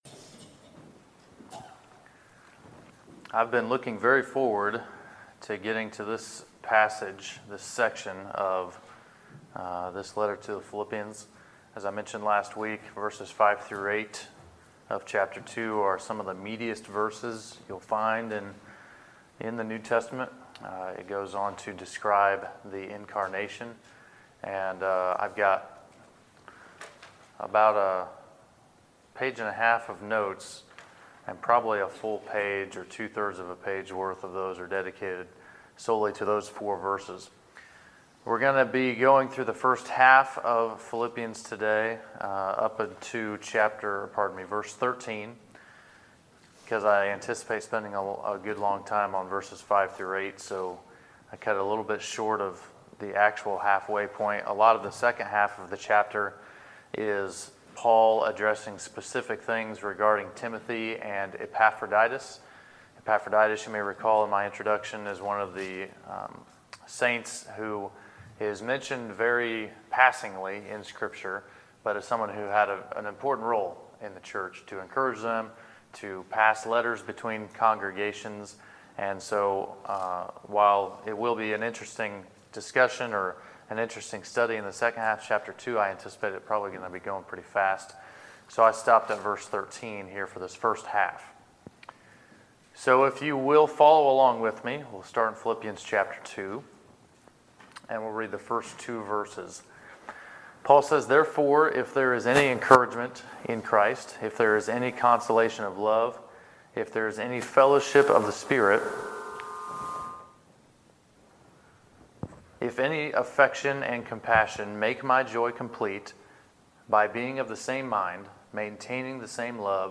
Sermons, October 21, 2018